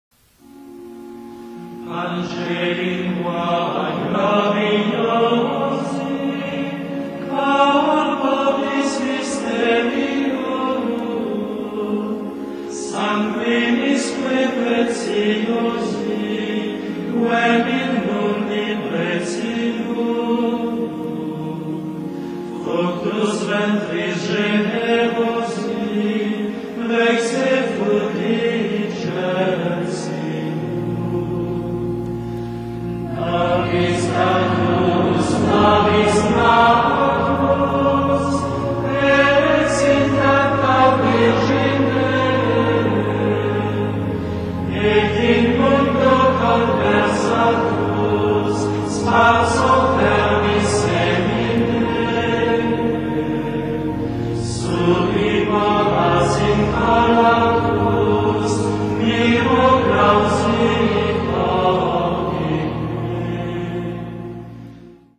Красивая любительская запись. Все хоралы исполнены под аккомпонимент органа.